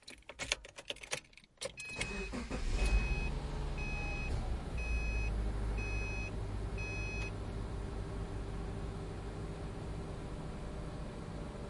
安全带开启
描述：汽车安全带被拉出并钩住了锁。